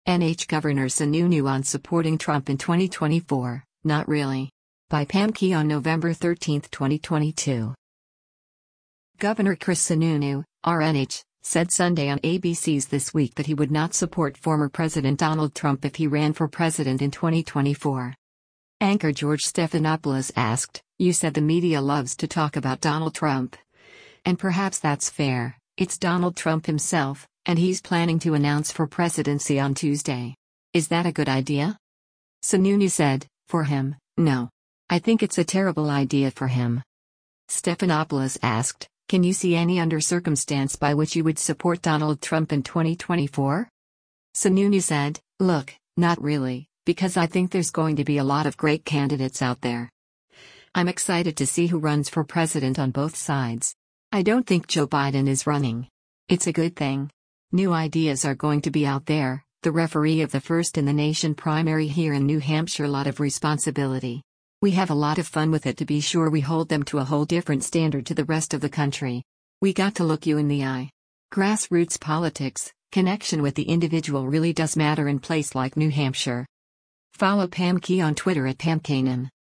Governor Chris Sununu (R-NH) said Sunday on ABC’s “This Week” that he would not support former President Donald Trump if he ran for president in 2024.